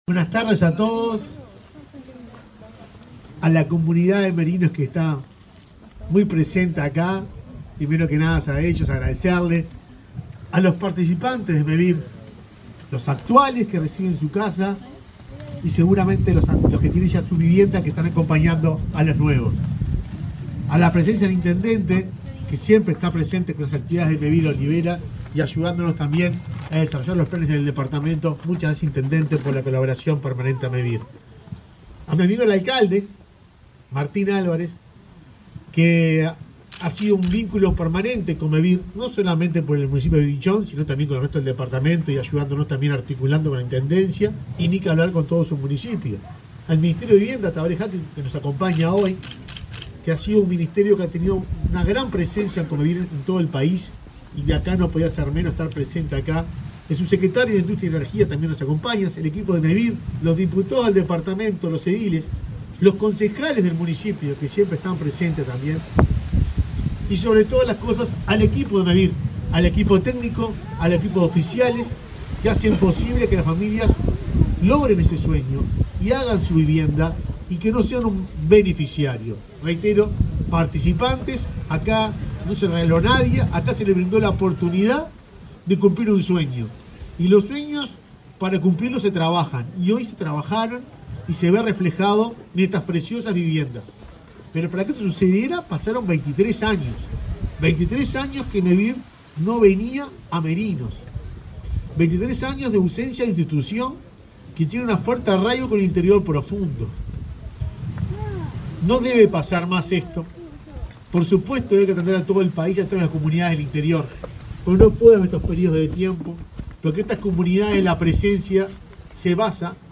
Palabras de autoridades en entrega de viviendas en Paysandú
Este martes 31, el presidente de Mevir, Juan Pablo Delgado, el subsecretario de Industria, Walter Verri, y su par de Vivienda, Tabaré Hackebruch,